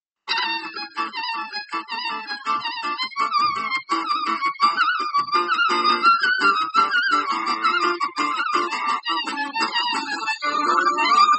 gypsy fiddle
GYPSY-fiddle.mp3